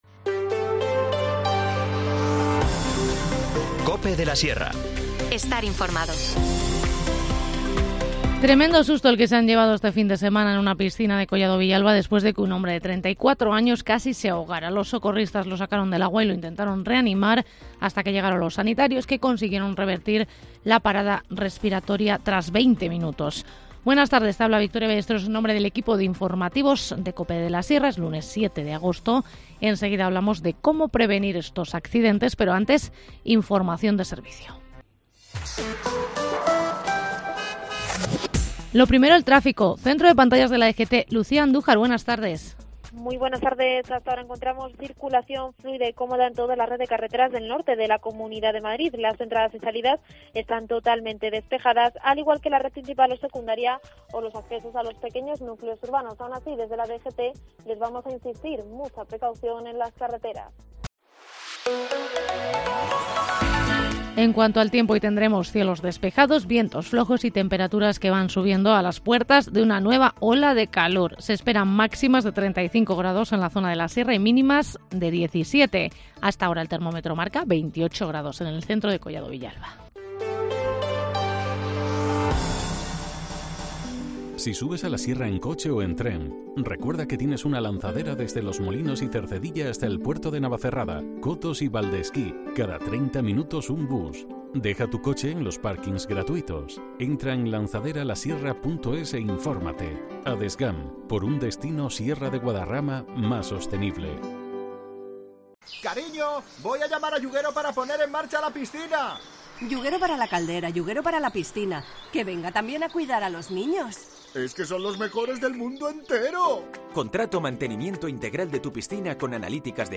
Informativo Mediodía 7 agosto